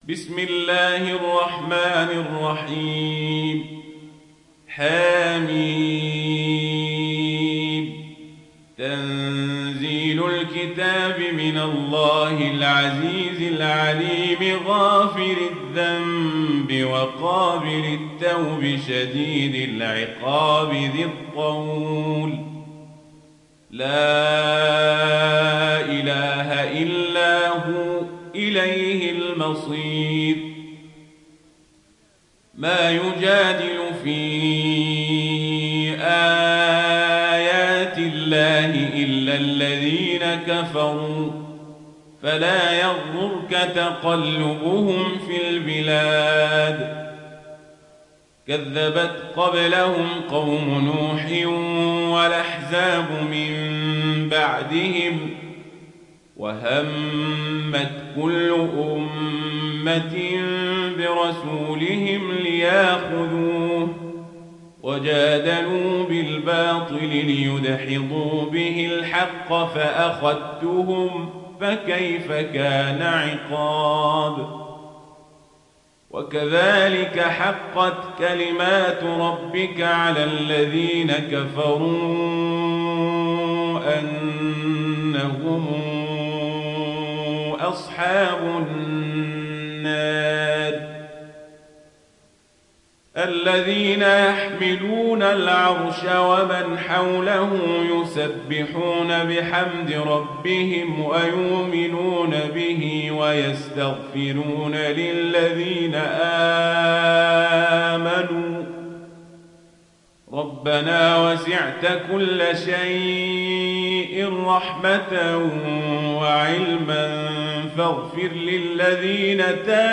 Riwayat Warsh dari Nafi